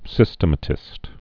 (sĭstə-mə-tĭst, sĭ-stĕmə-)